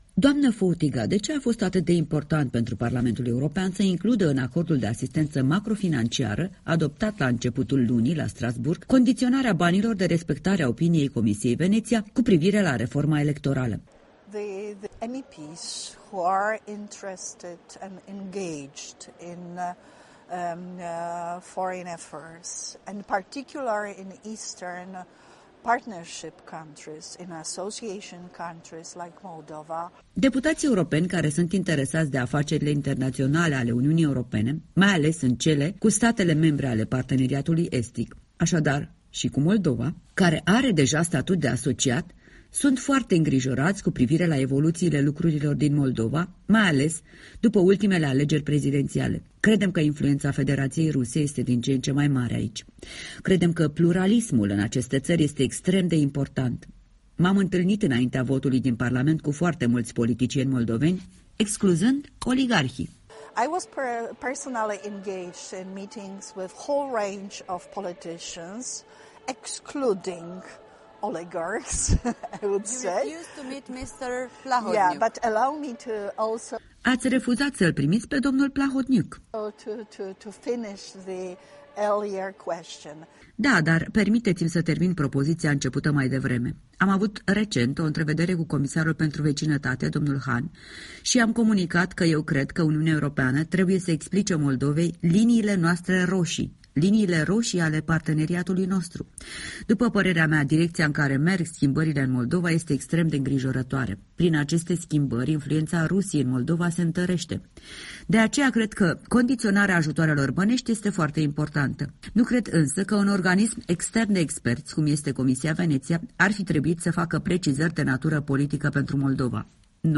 De vorbă la Strasbourg cu eurodeputata poloneză Anna Elzbieta Fotyga